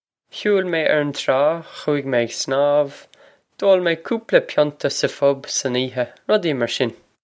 Pronunciation for how to say
Hew-il may air on traw, khoo-ig may egg sn-awv; d'ole may koopla pyon-ta sah fub son ee-ha...rud-ee marr shin.